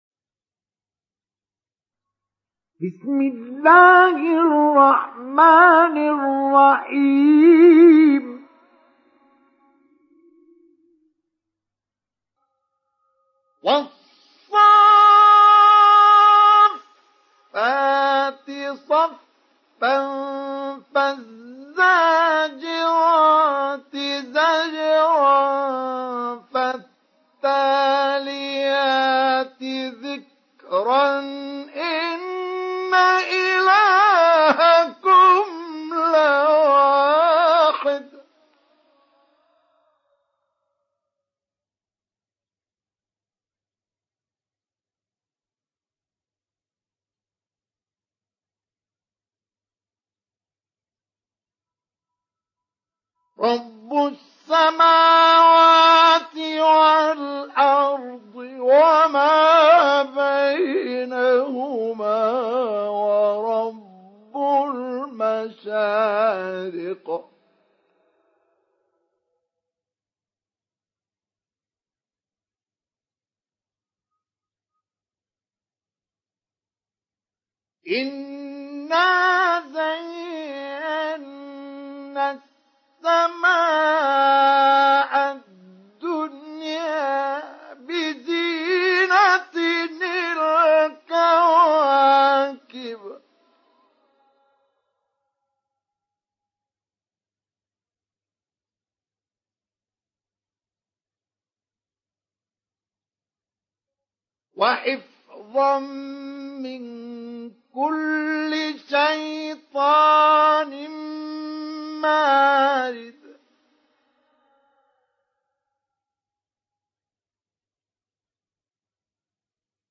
Saffet Suresi İndir mp3 Mustafa Ismail Mujawwad Riwayat Hafs an Asim, Kurani indirin ve mp3 tam doğrudan bağlantılar dinle
İndir Saffet Suresi Mustafa Ismail Mujawwad